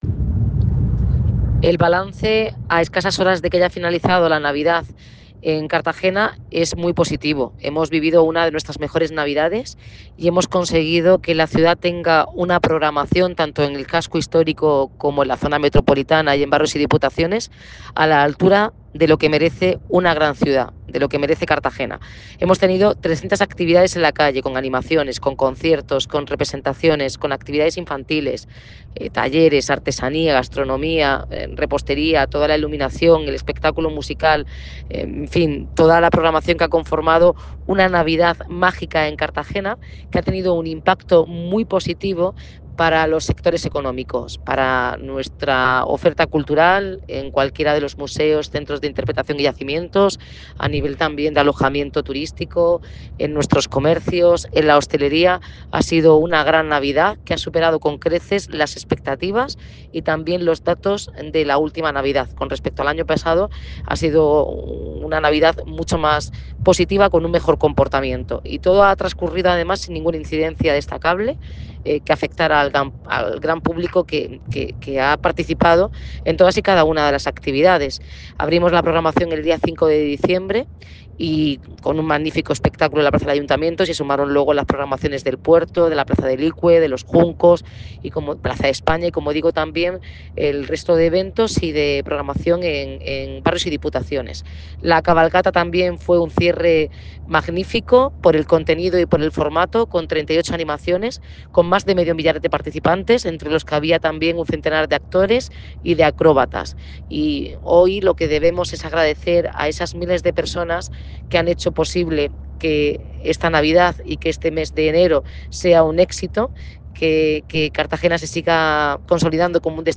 Enlace a Declaraciones de la alcaldesa, Noelia Arroyo.